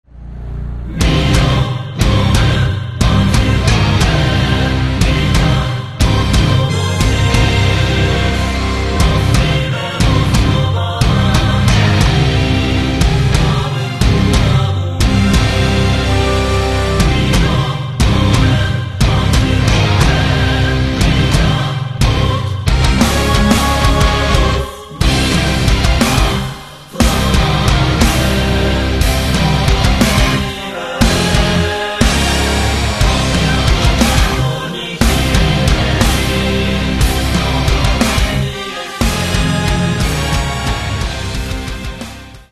Каталог -> Рок та альтернатива -> Енергійний рок
Альтернативна музика?
Мелодійний хеві-метал?